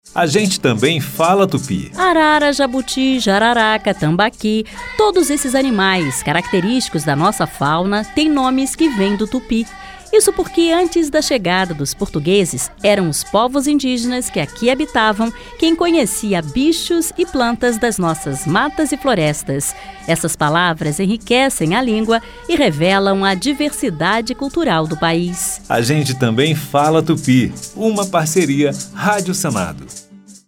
A Rádio Senado preparou o sexto grupo de dez spots da série “A gente também fala tupi”.